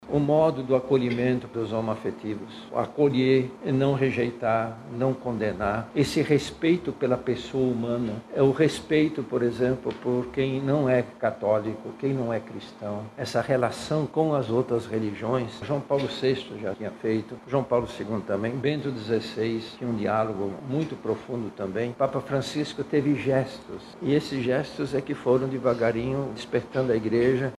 Em entrevista coletiva, na sede da Arquidiocese de Manaus, na manhã de hoje, o arcebispo metropolitano e cardeal da Amazônia, Dom Leonardo Steiner, disse que Sua Santidade quebrou vários paradigmas ao longo do seu pontificado.